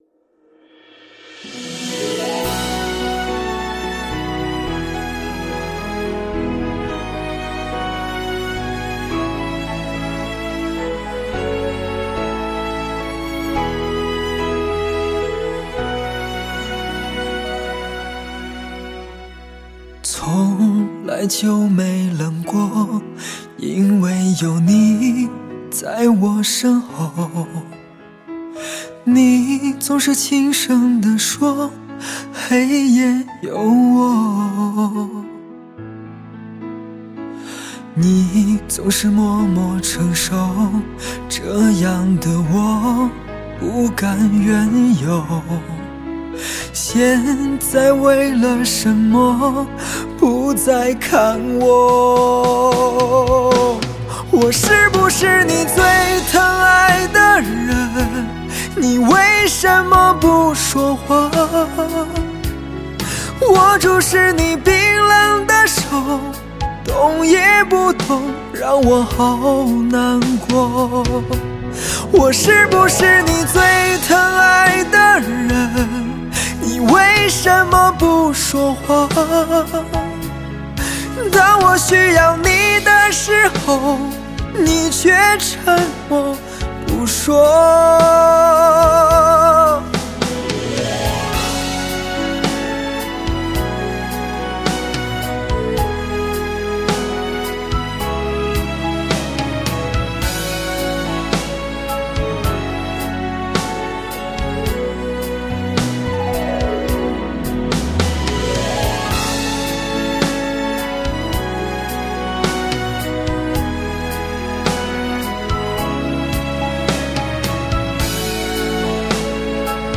抒发心灵放松的解药，抚慰心灵的疗伤音乐，内心情感世界的告白。
现代发烧深情代表作，首席疗伤音乐男声魅力。
本世纪最真实的声音，无可挑剔最深情的男声。